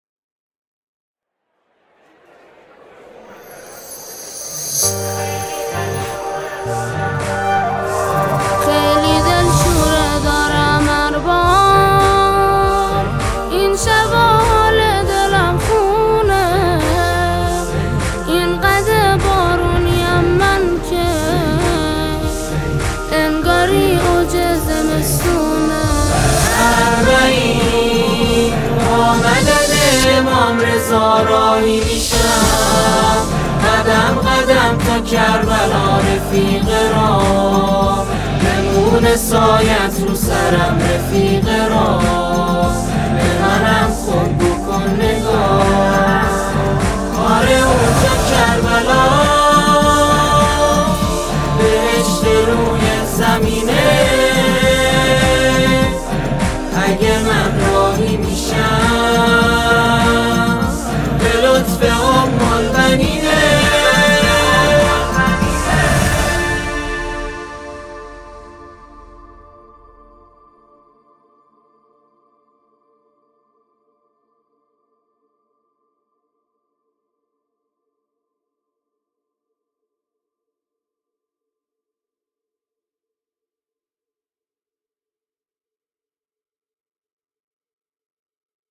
گروه سرود یزد